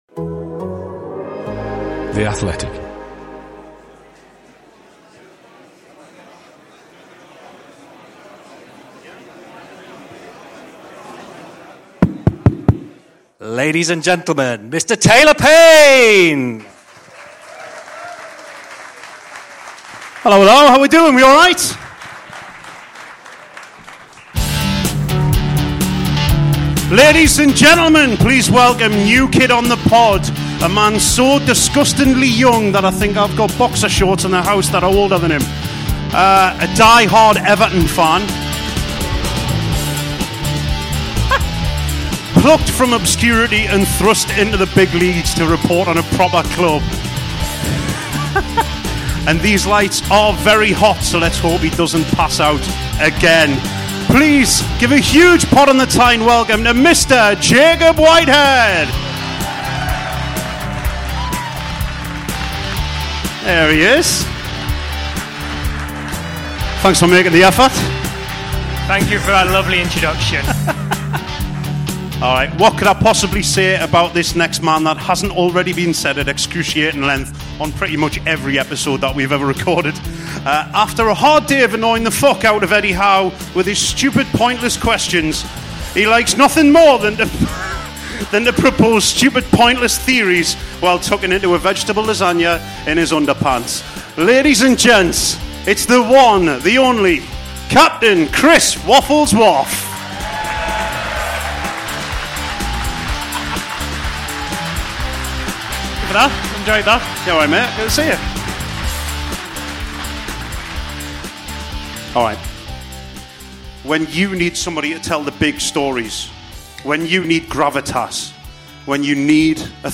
PotT LIVE at the Gosforth Civic Theatre!
Live from the Gosforth Civic Theatre in the heart of *checks notes* Newcastle's most middle class suburb, it's our stage debut!